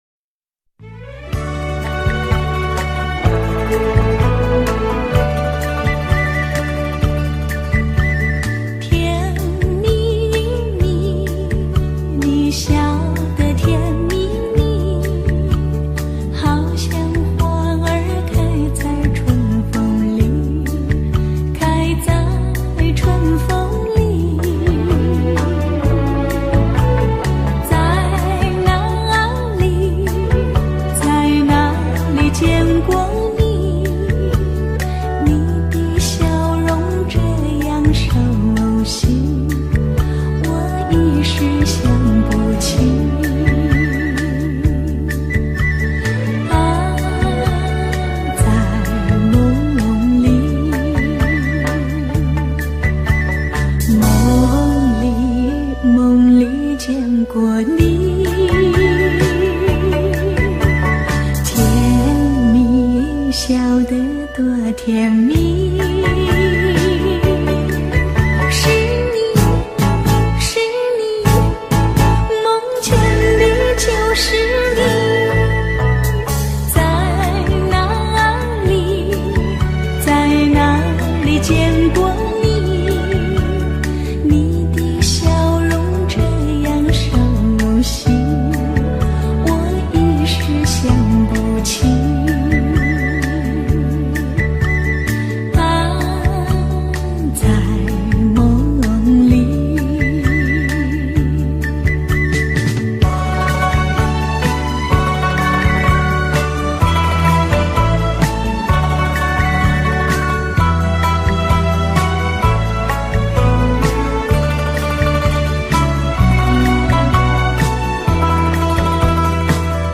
Popular Chinese Song